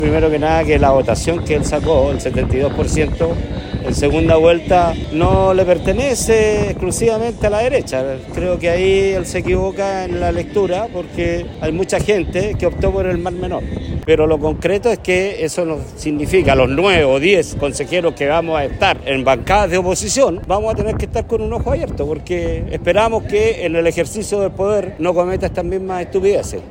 Durante la ceremonia juraron 27 consejeros regionales de los distintos distritos de la región, que serán encargados de fiscalizar al GORE.
Entre ellos, Marcelo Rivera, core por Concepción 1, de la centroizquierda, quien cuestionó el discurso de Sergio Giacaman y su autodenuncia luego de conducir a exceso de velocidad.